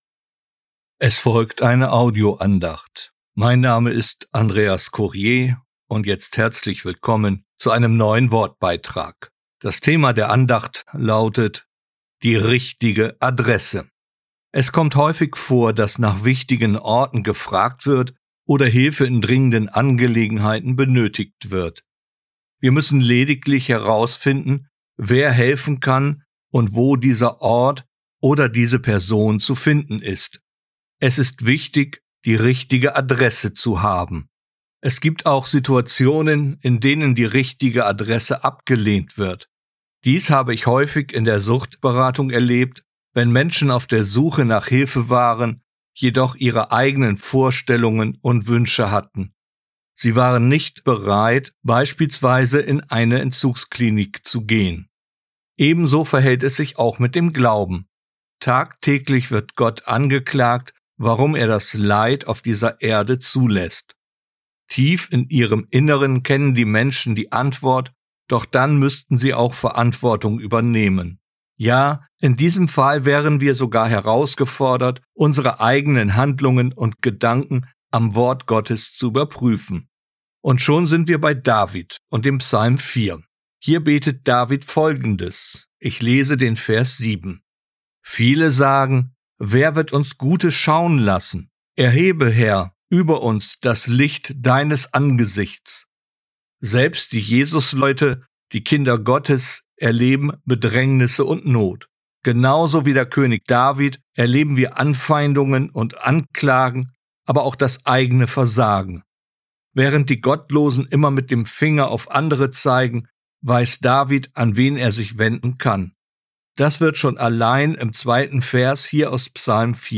Die richtige Adresse, eine Audioandacht